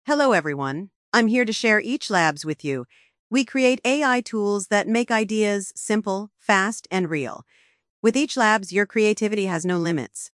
chatterbox-speech-to-speech-output.mp3